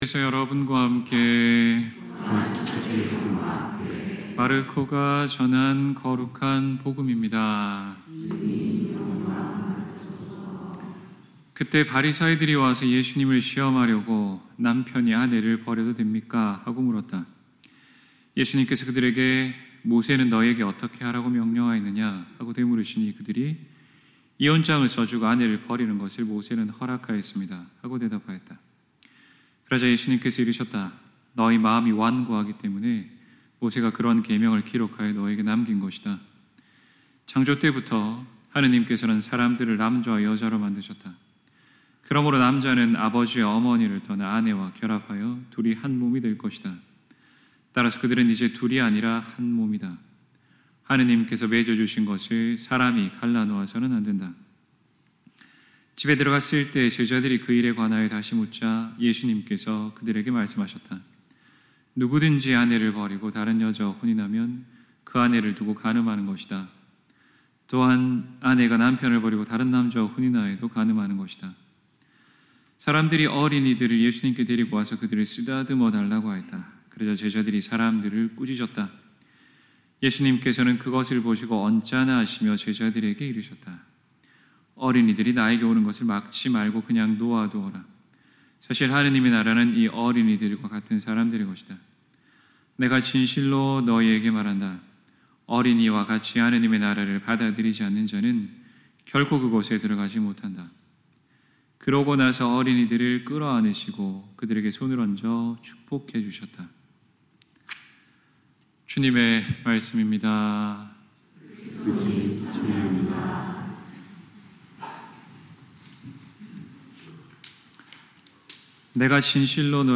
2024년10월 06일 연중 제27주일 신부님 강론